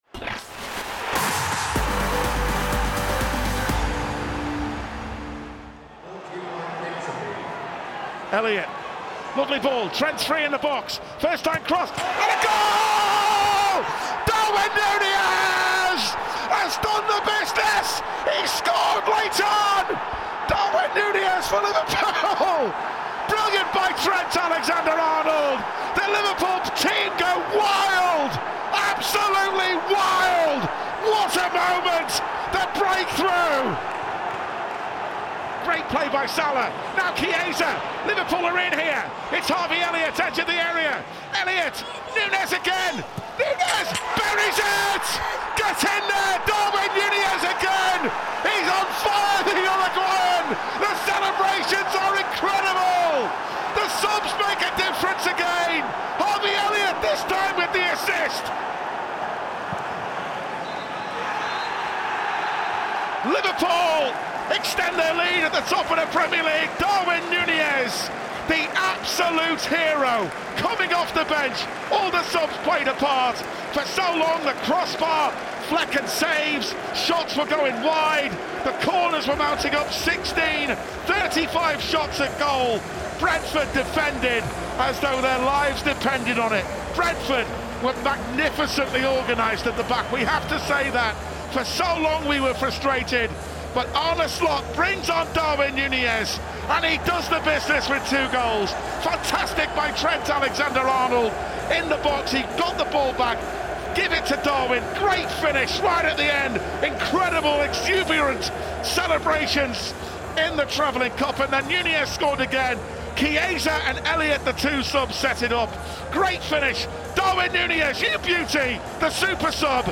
Arne Slot and Harvey Elliott give us their verdict on Liverpool's dramatic 2-0 win over Brentford in the Premier League.
In the LFCTV studio